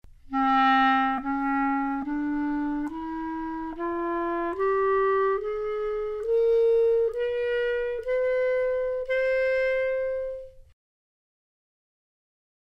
One-Octave Microtonal Scales
Scales can be formed from microtones that present fascinating pitch or timbre relationships when written for E-flat clarinet.
Example #10 presents a ten-note scale in the chalumeau register that exploits dark timbres.
15 - E-flat Clarinet - Track 15 - CD2.mp3